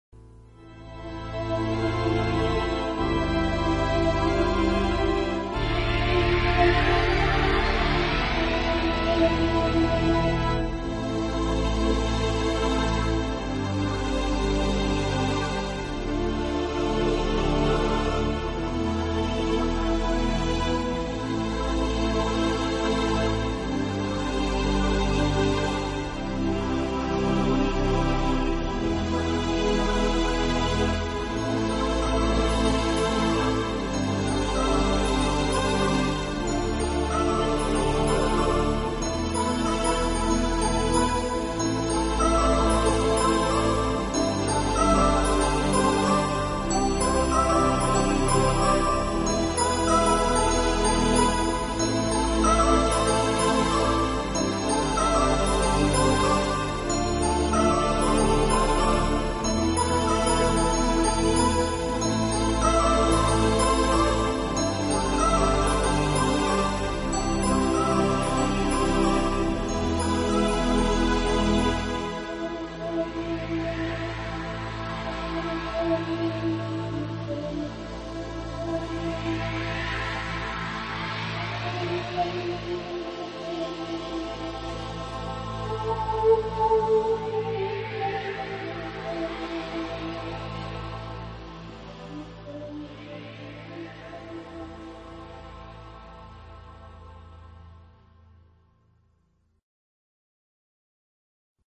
专辑语言：纯音乐
这种音乐是私密的，轻柔的，充满庄严感并总是令人心胸开阔。